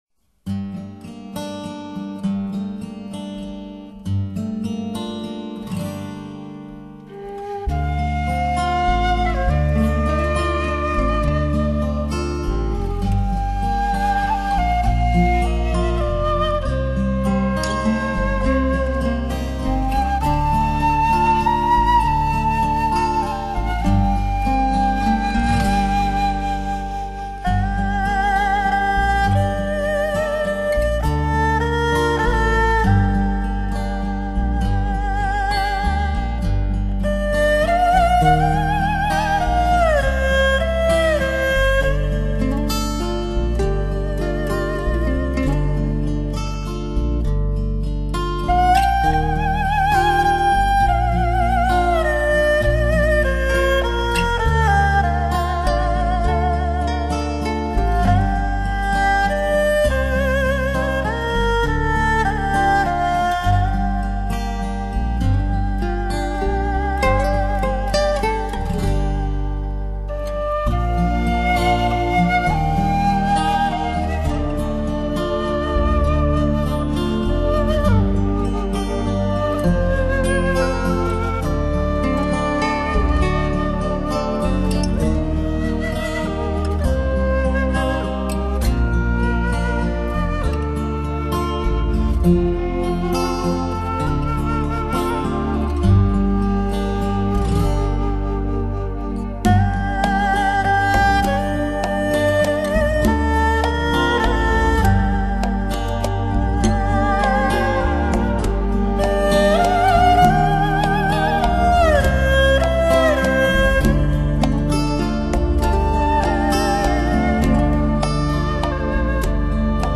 该版的DTS版本已经在网上铺天盖地，但是如果你没有相应设备，还不如静静地欣赏这个STEREO版本 。